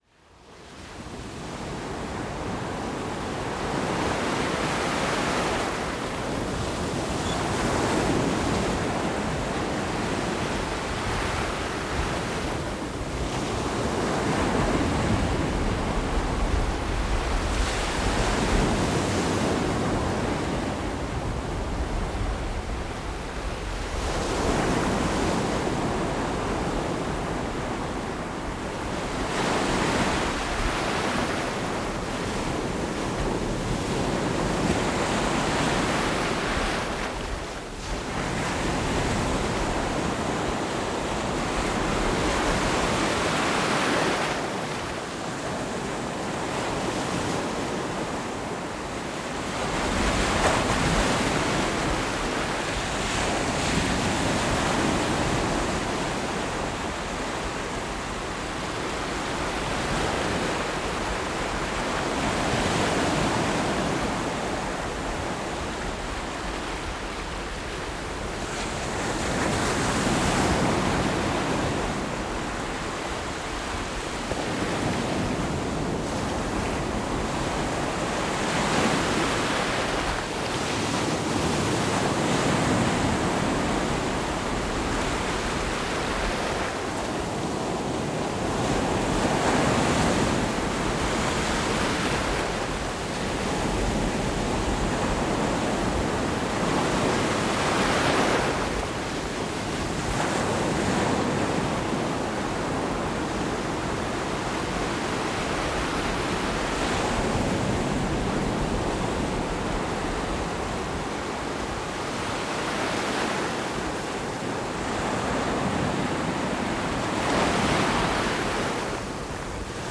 # AudioMo On the afternoon of November 7, 2014, I was at Clearwater Beach, Florida, when I decided to stand in the surf with my Zoom H6 and it's X/Y microphone capsules, and recorded a few minutes of the ocean waves.